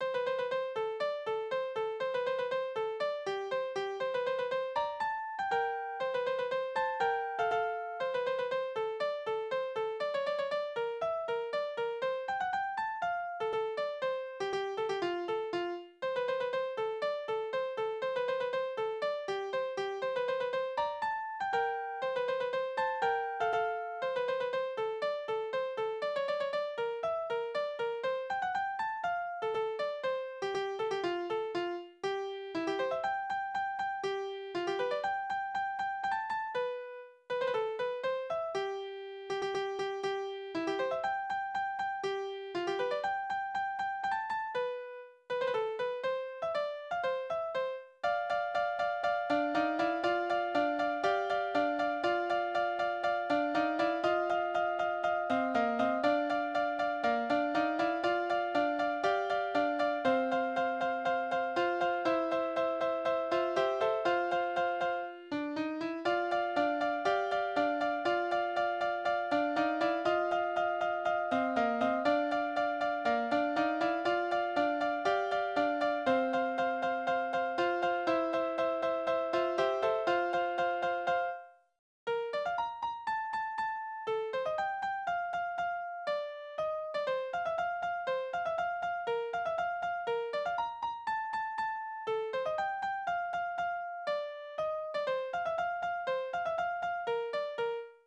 "Schlittenfahrt" Polka
Tonart: F-Dur, C-Dur, B-Dur Taktart: 2/4
Besetzung: vokal